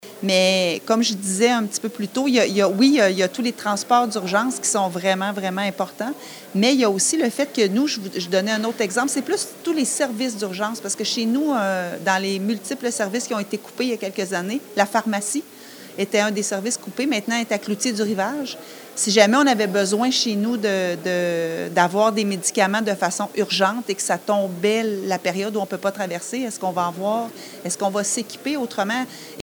La décision rassure la mairesse de Nicolet, Geneviève Dubois, qui signale toutefois que certaines questions restent encore sans réponse